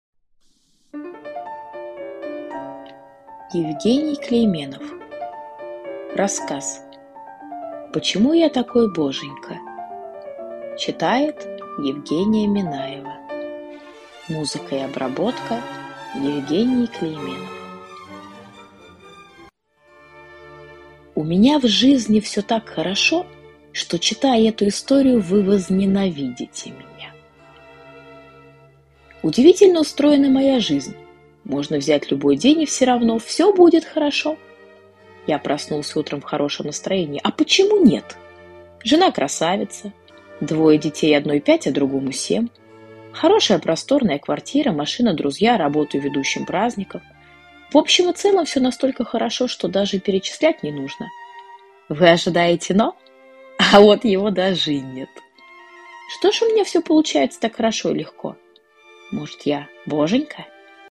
Аудиокнига Почему я такой Боженька?